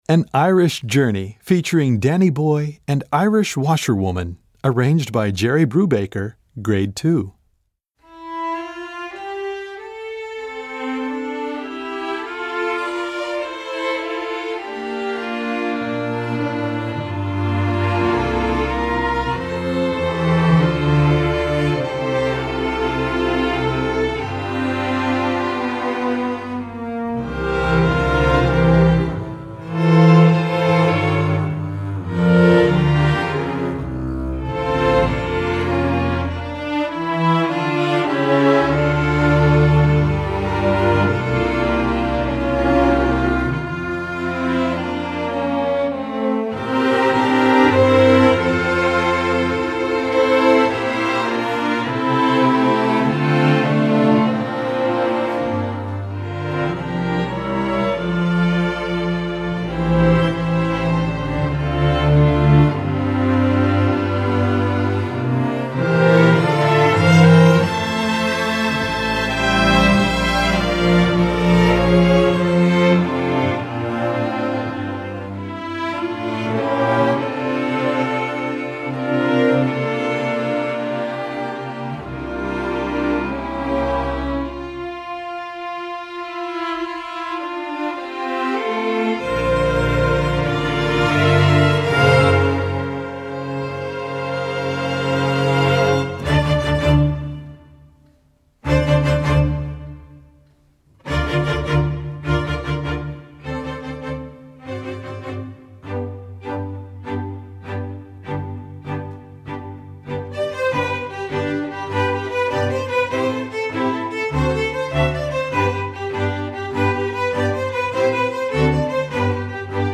Gattung: Streichorchester
Besetzung: Streichorchester